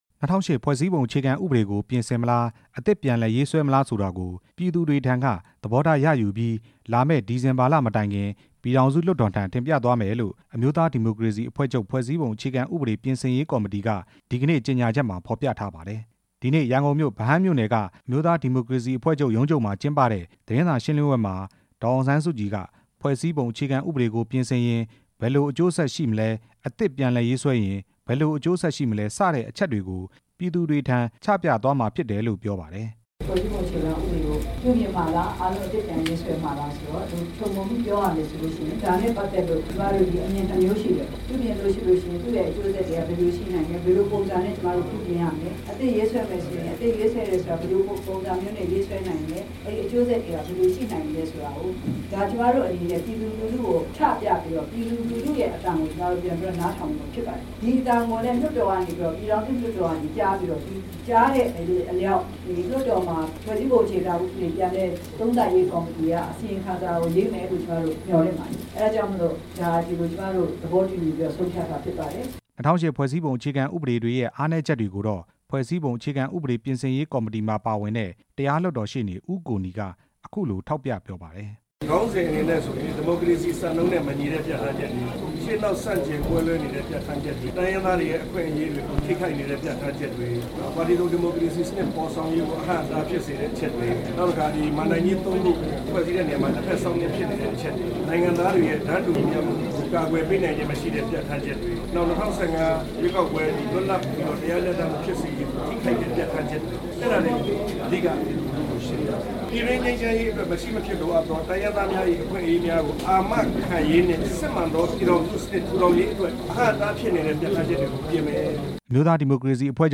ရန်ကုန်မြို့ အမျိုးသားဒီမိုကရေစီအဖွဲ့ချုပ် ပါတီရုံးချုပ်မှာကျင်းပတဲ့ သတင်းစာရှင်းလင်းပွဲမှာ ဒေါ်အောင်ဆန်းစုကြည်က ၂ဝဝ၈ ဖွဲ့စည်းပုံ အခြေခံဥပဒေဟာ ပြင်ကိုပြင်ရမယ်လို့ ယုံကြည်ကြောင်း အခုလို ပြောပါတယ်။
အမျိုးသားဒီမိုကရေစီအဖွဲ့ချုပ် ဖွဲ့စည်းပုံ အခြေခံဥပဒေ ပြင်ဆင်ရေးကော်မတီရဲ့ ဒီနေ့ ထုတ်ပြန်ကြေညာချက်နဲ့ ပတ်သက်လို့ RFA သတင်းထောက်